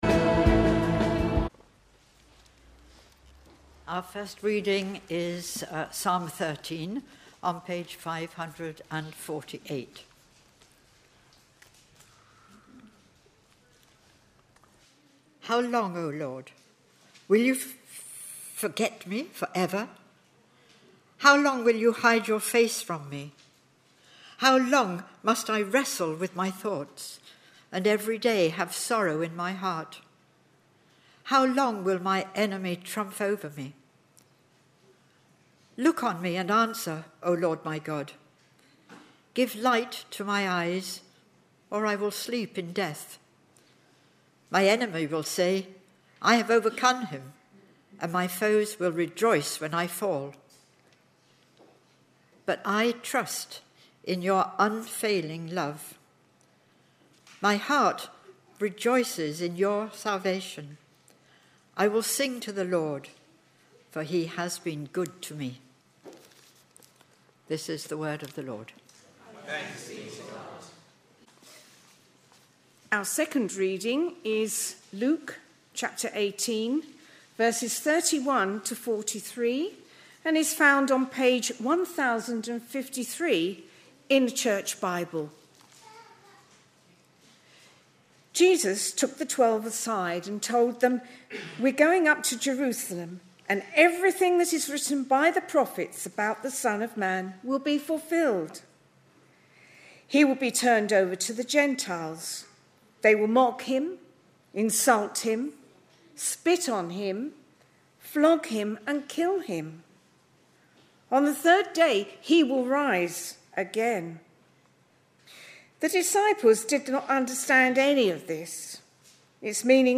Readings-Sermon-on-9th-March-2025.mp3